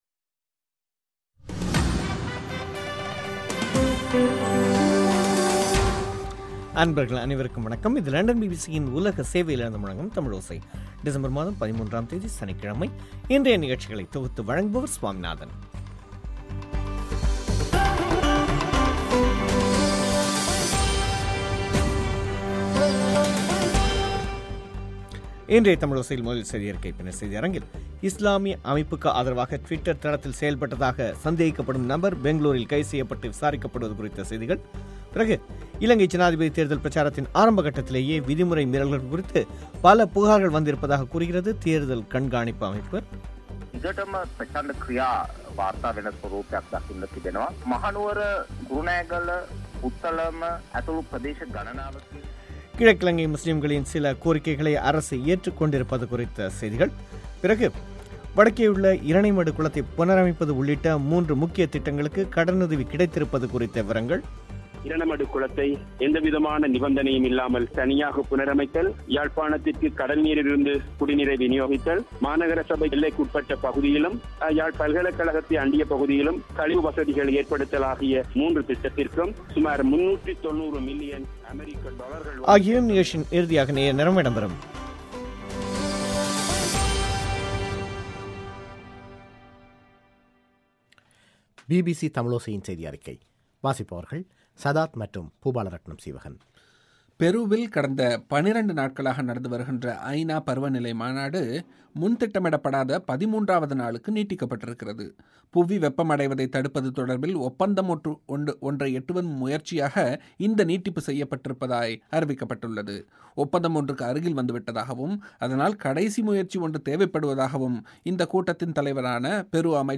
முக்கியச் செய்திகள்